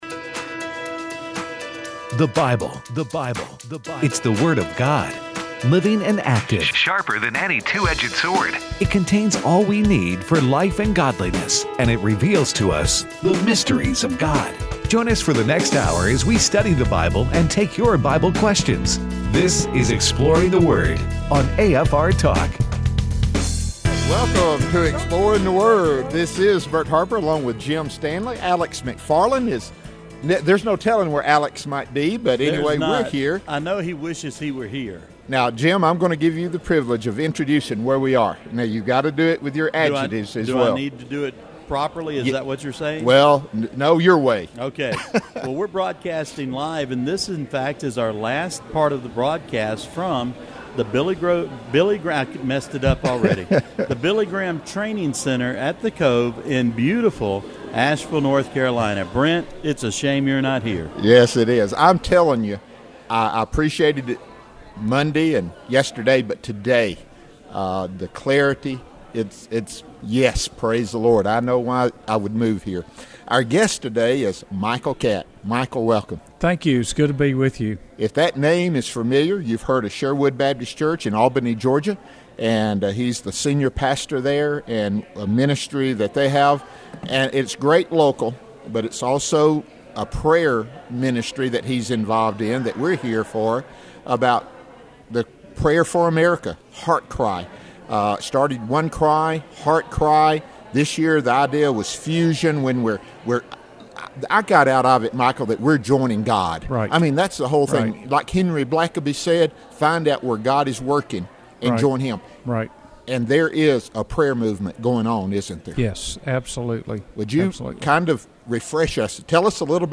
Live at Fusion Day 2